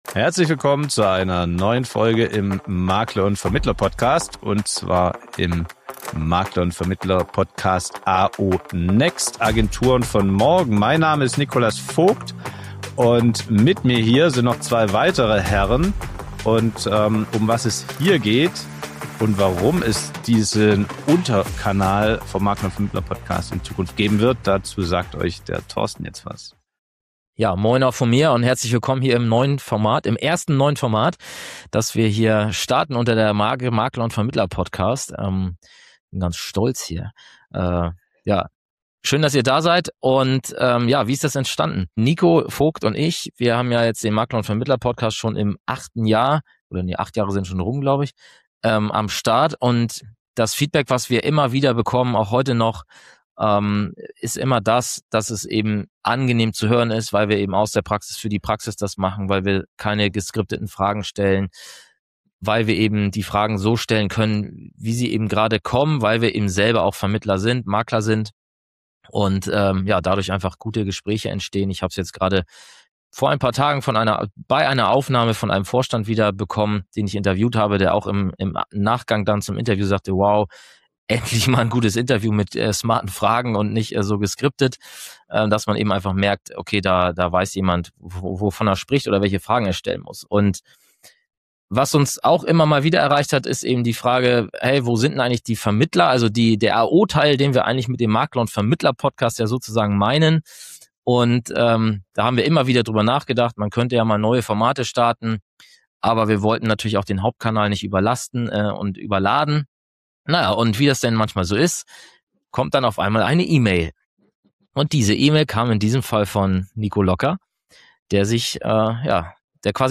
Freut euch auf ein spannendes Gespräch über moderne Agenturarbeit, Wissenstransfer zwischen den Vertriebsschienen und den gesellschaftsübergreifenden Austausch, der Agenturen und Vermittler weiter voranbringen soll.